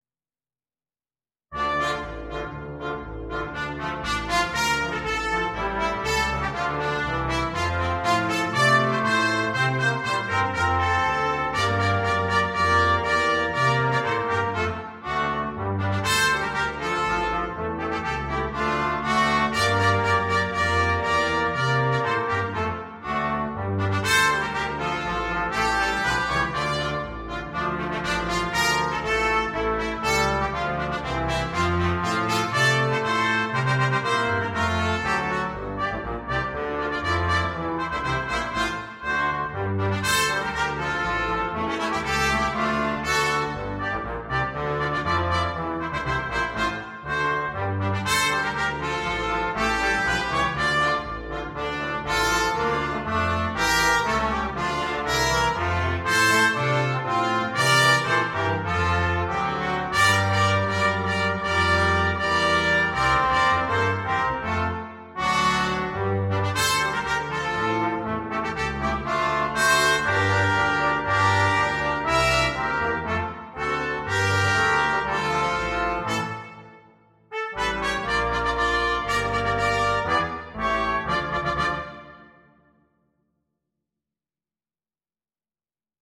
Ноты для брасс-квинтета